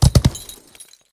gallop1.ogg